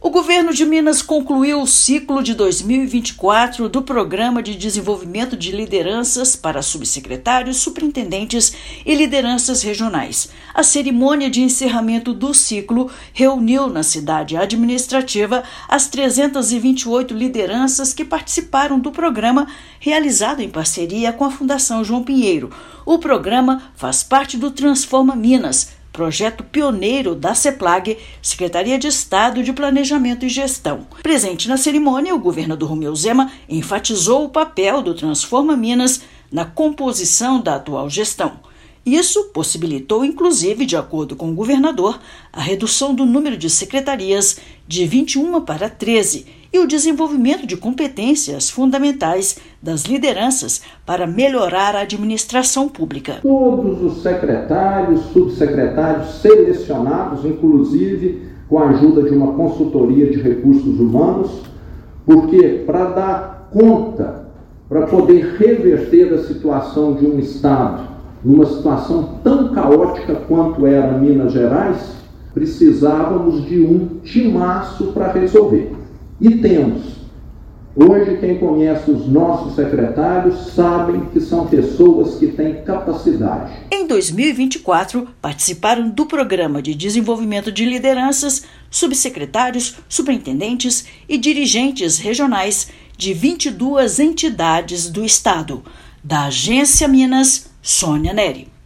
[RÁDIO] Governo de Minas conclui ciclo 2024 do Programa de Desenvolvimento de Lideranças e avança no aprimoramento da gestão pública
PDL é uma das frentes do Transforma Minas, que selecionou mais de 500 lideranças para cargos de gestão no Estado, em cinco anos. Ouça matéria de rádio.